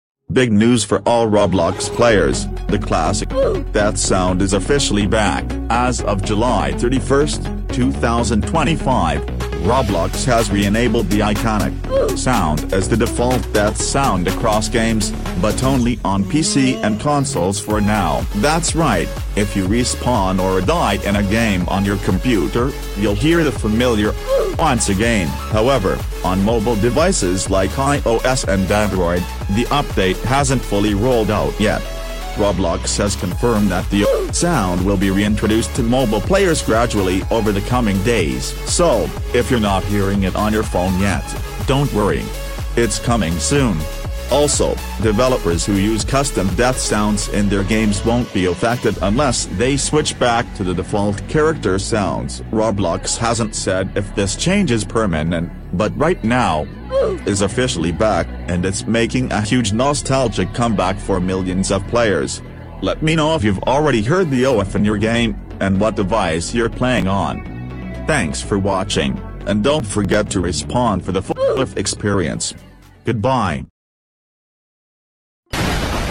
OOF sound is back!!! (2025) sound effects free download